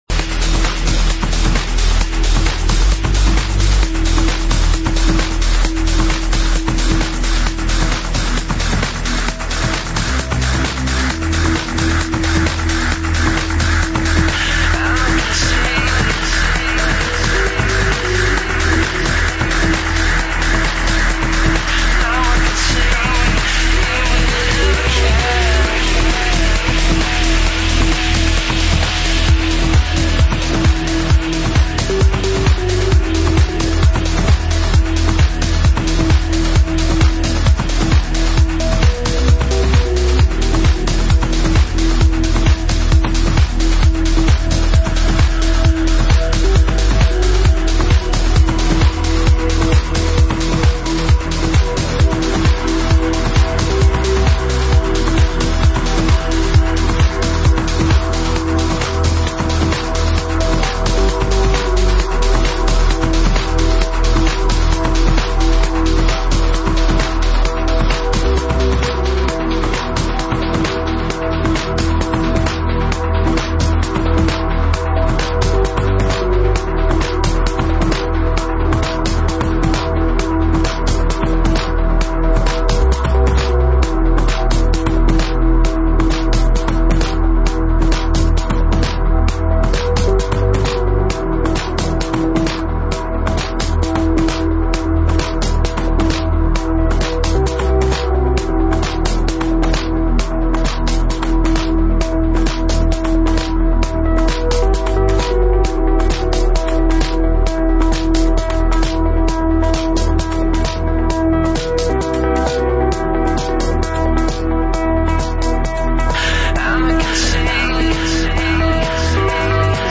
Красивый и мелодичный Транс!
Подстиль: Trance / Progressive Trance